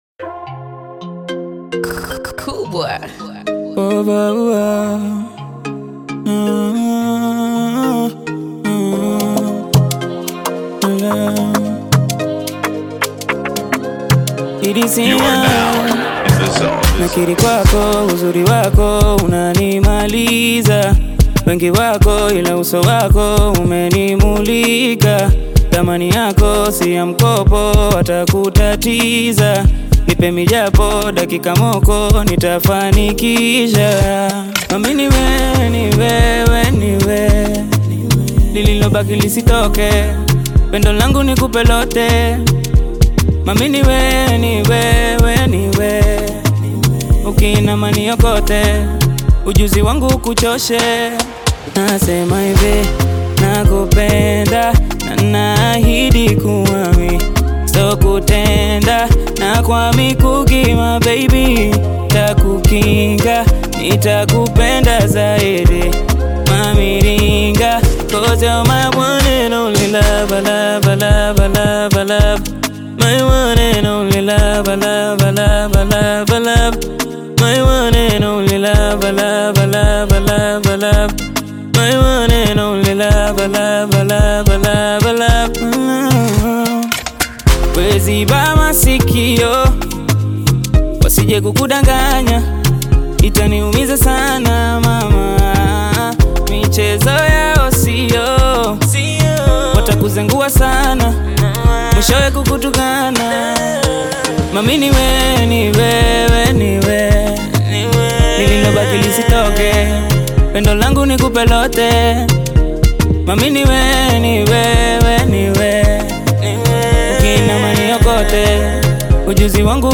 bongo flava
love song
African Music